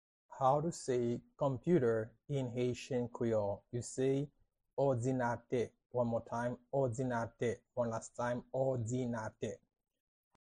How to say “Computer” in Haitian Creole – “Òdinatè” pronunciation by a native Haitian teacher
How-to-say-Computer-in-Haitian-Creole-–-Odinate-pronunciation-by-a-native-Haitian-teacher.mp3